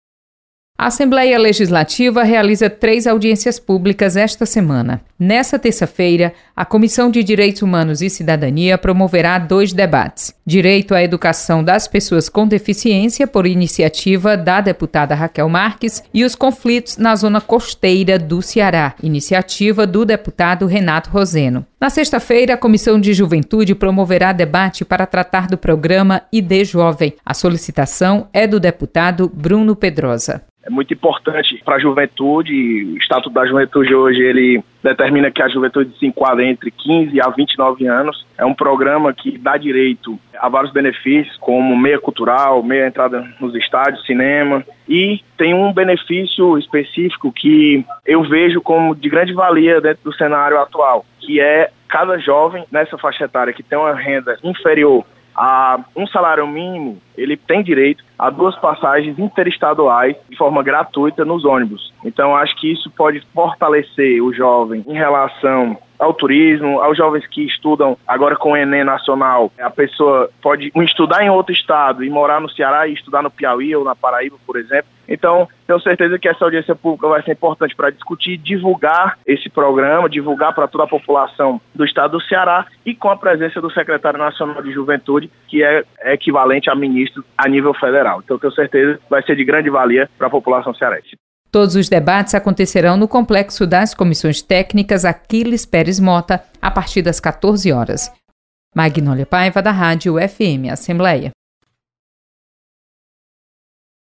Você está aqui: Início Comunicação Rádio FM Assembleia Notícias Comissão